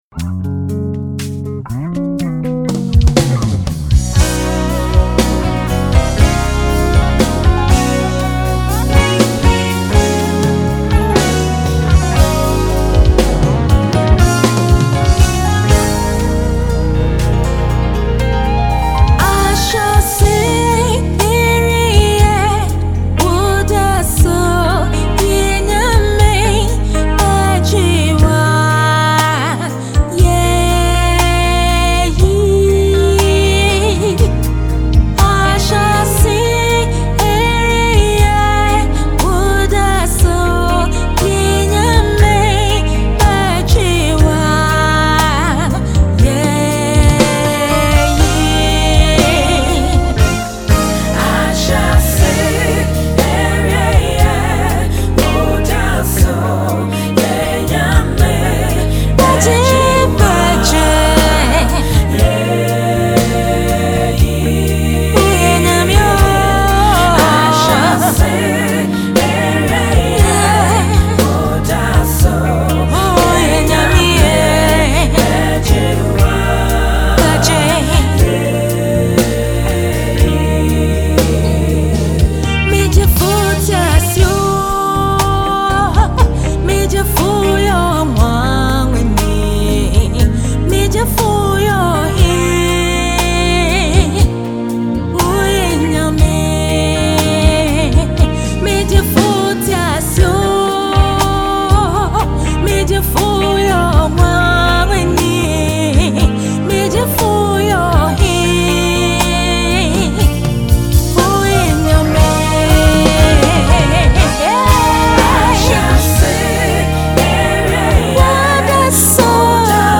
GospelMusic
a very soulful singer with a swooning voice